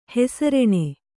♪ hesareṇe